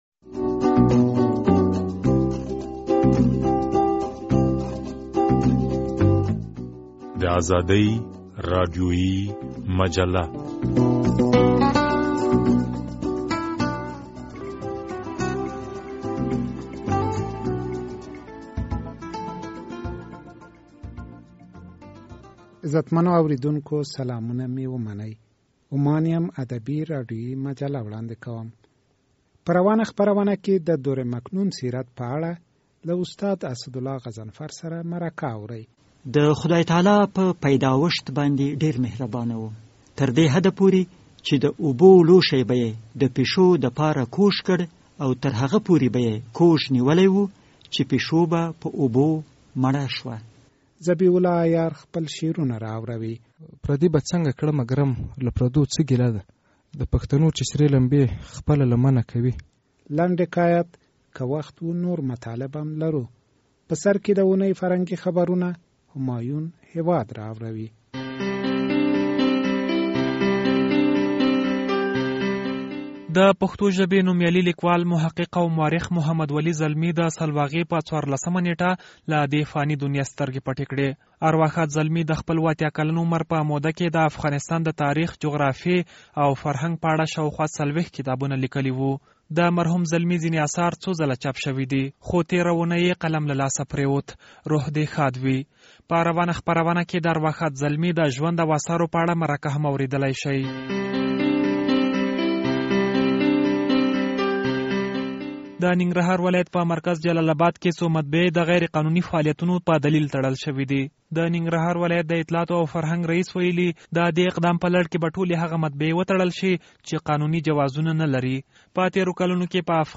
ازادي راډیويي مجله